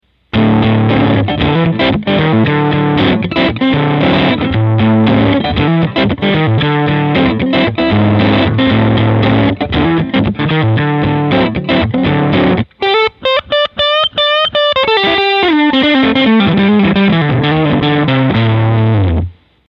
I used my loop station to record a 20 second guitar riff used it to record 5 different amp settings before I modded the amp.
I cranked the volume, which saturates the preamp stage with gain, and left the treble, bass and middle knows at 12.
The distortion in the “before” example has a clear articulation to it, while improved bass in the “after” example simply muddies the sound up.